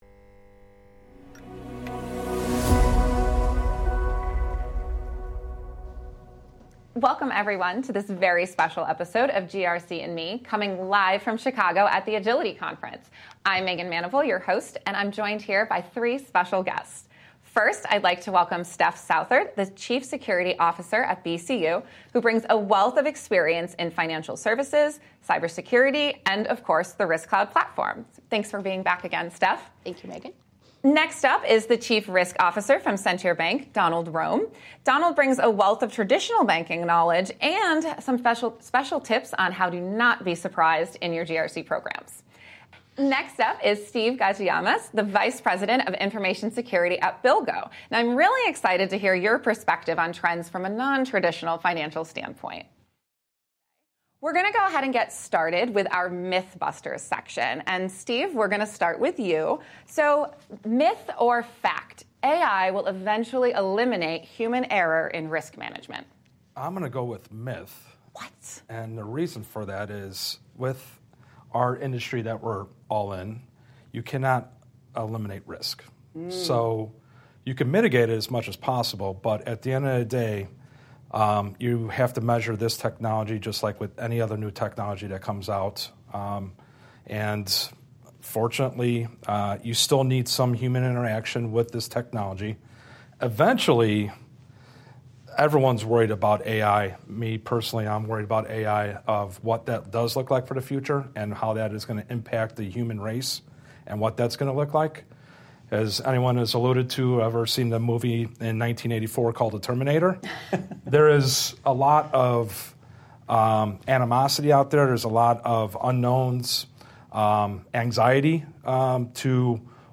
Don’t miss out on a thought provoking and engaging conversation on the ever-evolving financial risk landscape.